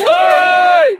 a crowd yelling "BUFFS"
a-crowd-yelling-buffs-7hggfjse.wav